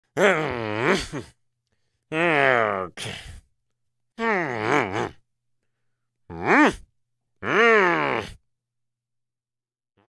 Angry sound effect